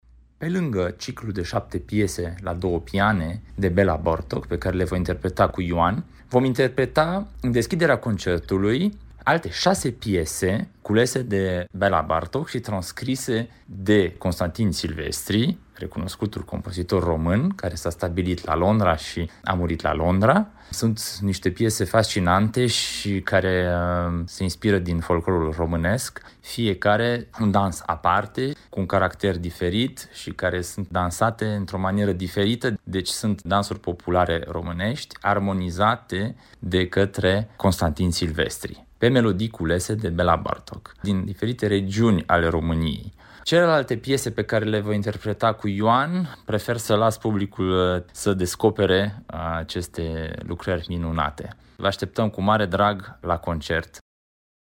interviuri, pentru Radio Timișoara, cu pianiștii